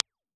Toshiba Type G - Cardioid ribbon microphone
Download impulse response file.